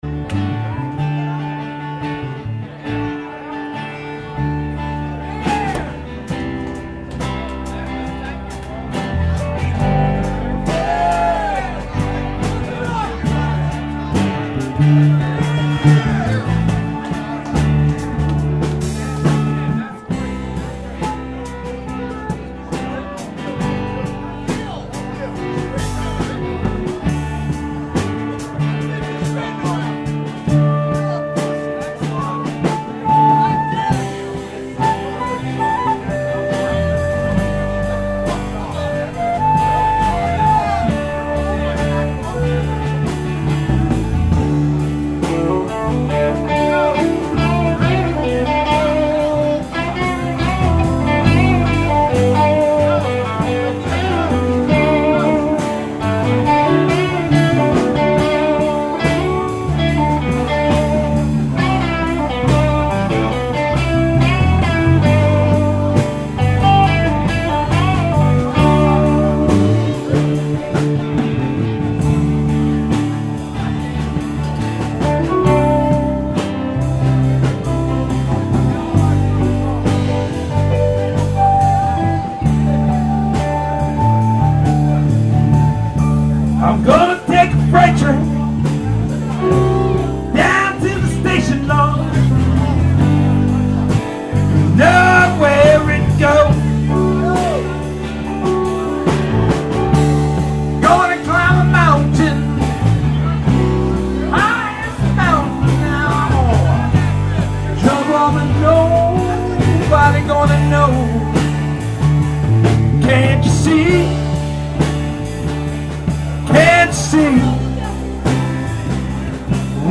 keyboards, backing vocals
sax, flute, tambourine, vocals